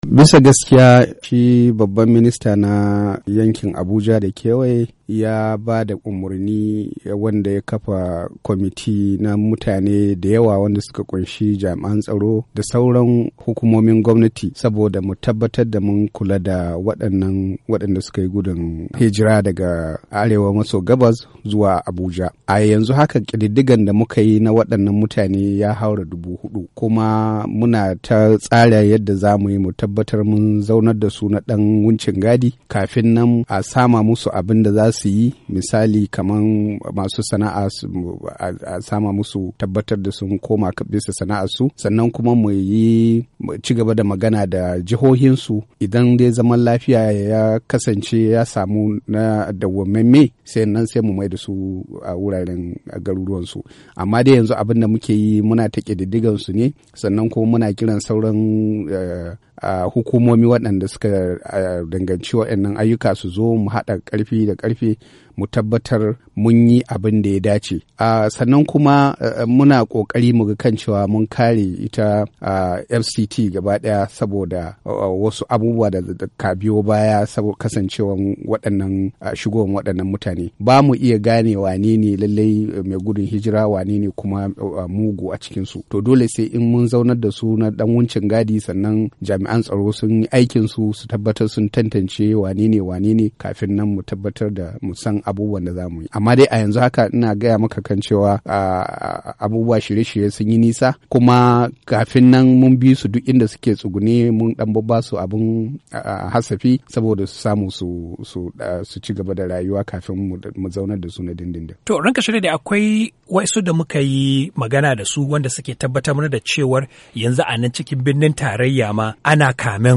Hukumomi a birnin tarayya na nuna damuwa dangane da yadda mutane ke tayin tururuwa suna shiga birnin tarayya domin neman mafaka. Mun samu zantawa da Alh. Abbas Idris, shugaban hukumar taimakon gaggawa FEMA ta birnin tarayyar Abuja, a wata ziyara da yakawo ma sashen Hausa a nan birnin...